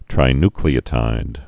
(trī-nklē-ə-tīd, -ny-)